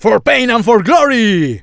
el_primo_start_vo_05.wav